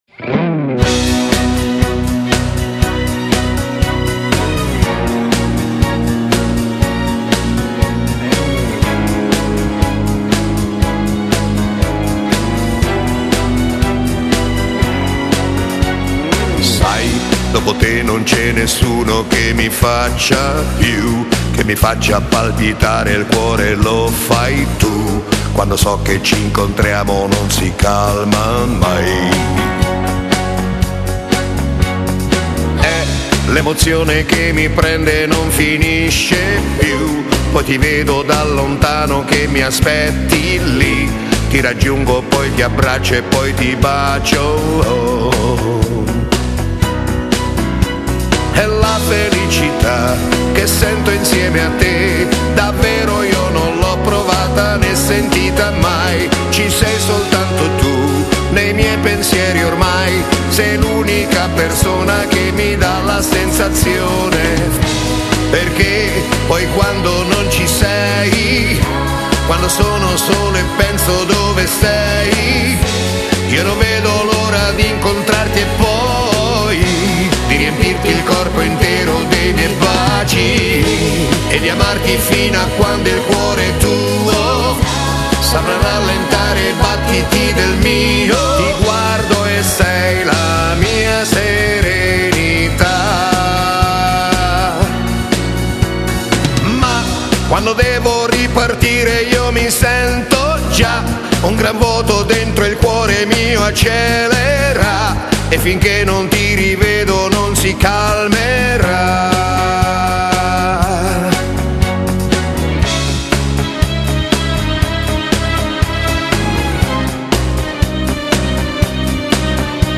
Genere: Beguine